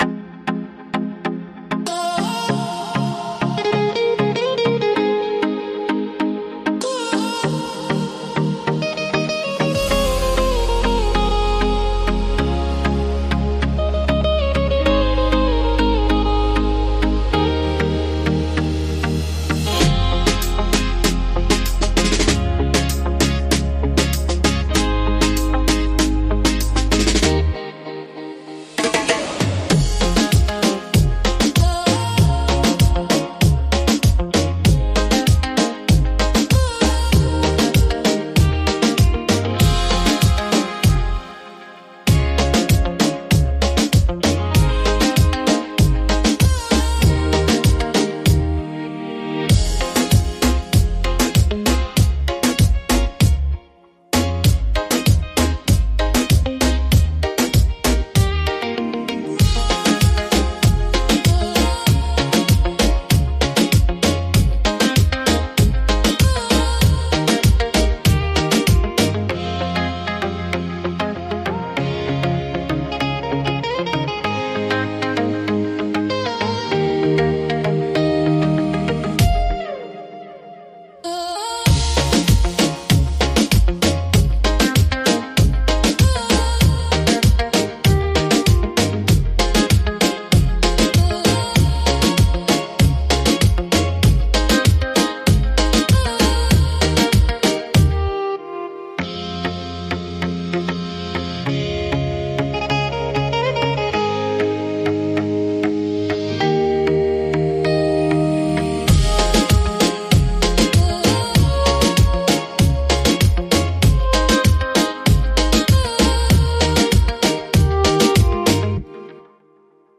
Beat Reggaeton Instrumental
Acapella e Cori Reggaeton Inclusi
G#